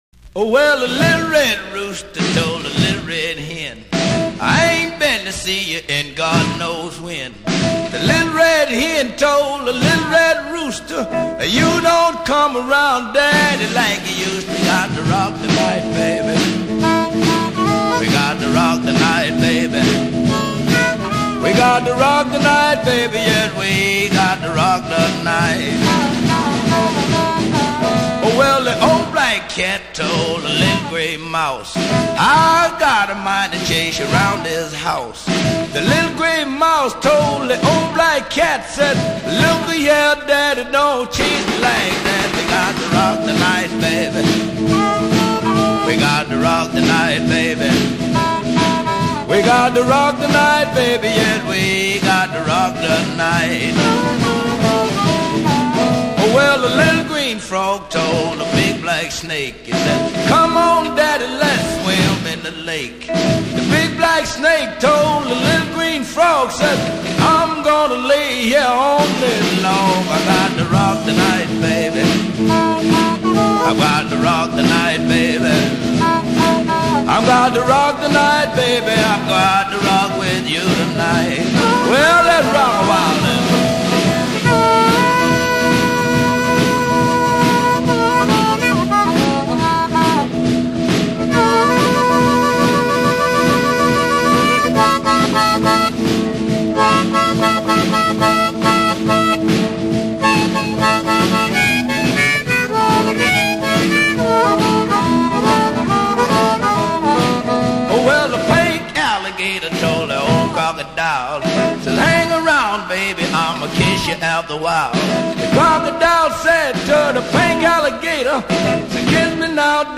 12 tracks of the finest Blues you may not have heard.